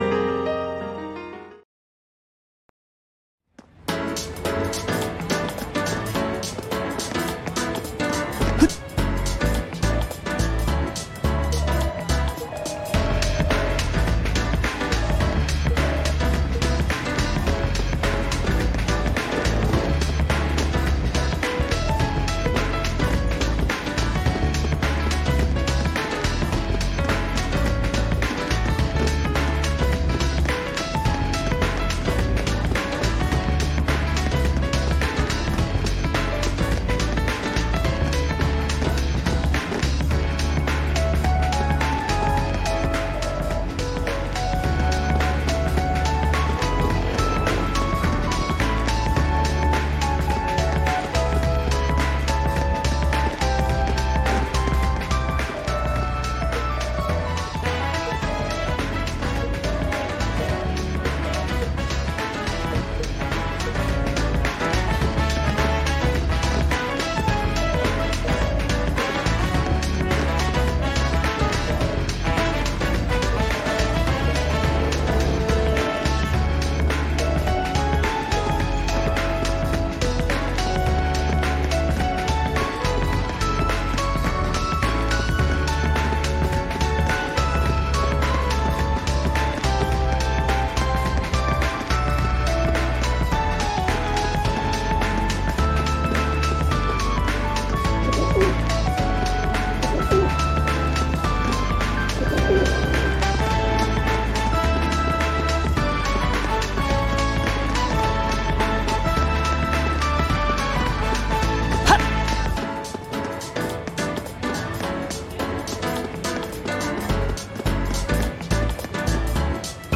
Game: Tokyo Xanadu Ex+ (Falcom) This footage was recorded years ago, so it might not be great.